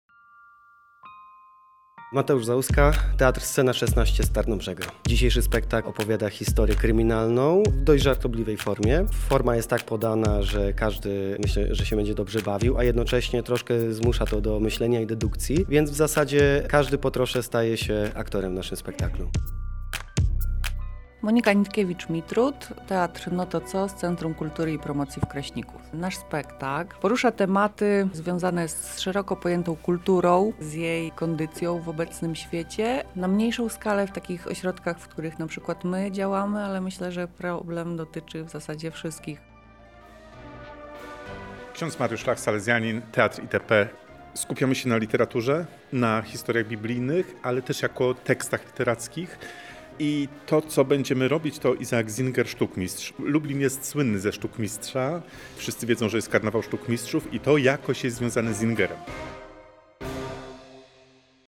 O swoich rolach i spektaklach mówią aktorzy: